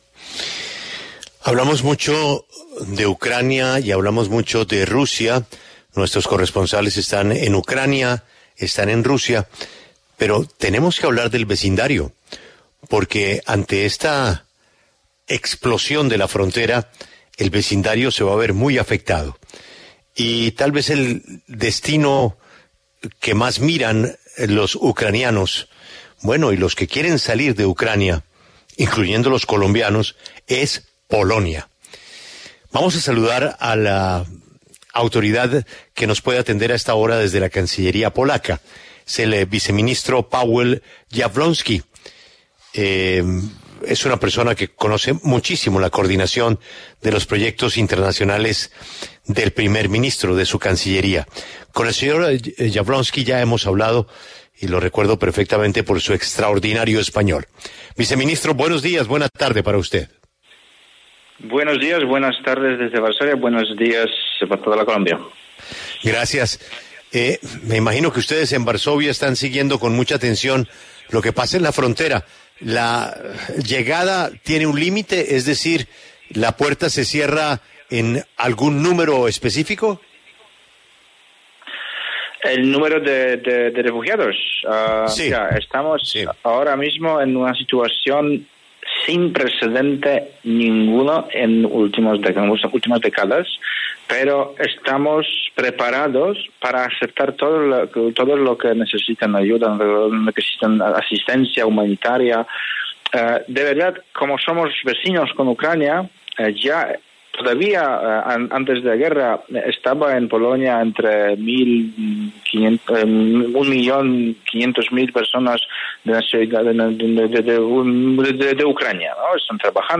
Pawel Jablonski, viceministro de Asuntos Exteriores de Polonia, habló en La W sobre la situación de los refugiados ucranianos en la frontera.